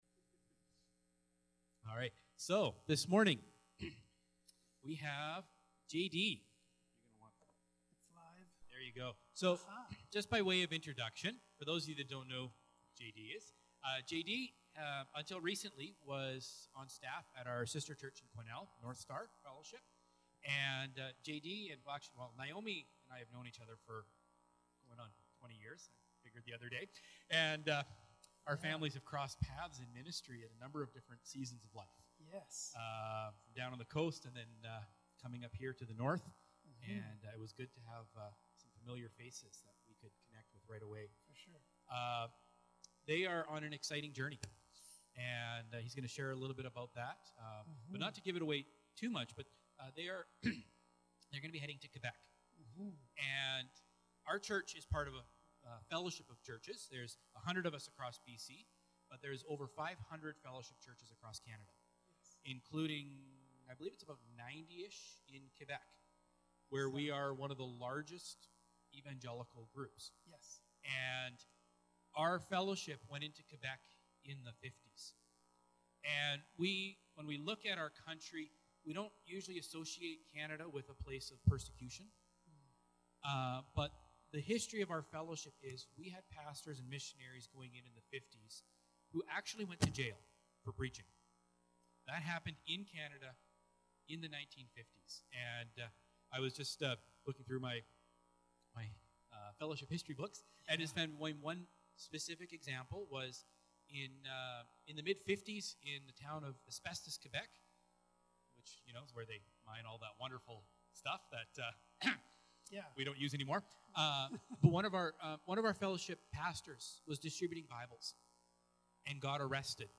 Sermons | Central Fellowship Baptist Church